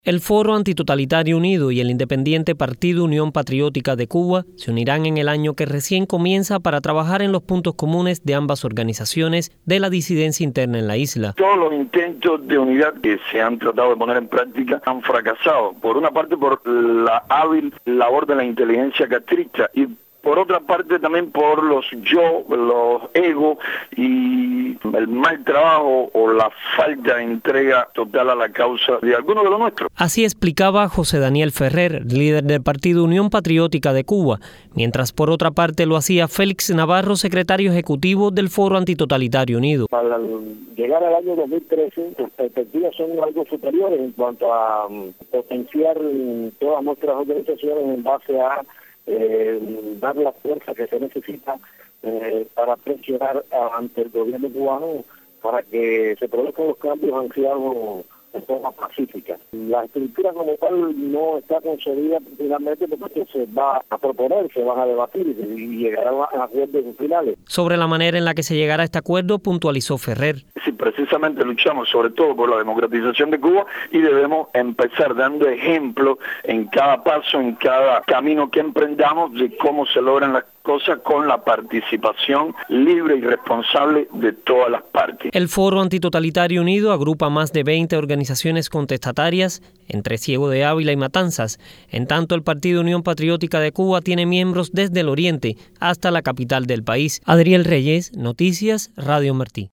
Reportaje radial